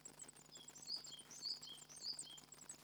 孵化後2〜3日経 過したときの雛の鳴き声は山型で数羽が一時に鳴き山型の音が1羽ずつの鳴き声。巣の外ではオスが鳴いている。